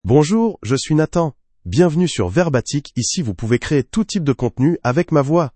Nathan — Male French (France) AI Voice | TTS, Voice Cloning & Video | Verbatik AI
Nathan is a male AI voice for French (France).
Voice sample
Listen to Nathan's male French voice.
Nathan delivers clear pronunciation with authentic France French intonation, making your content sound professionally produced.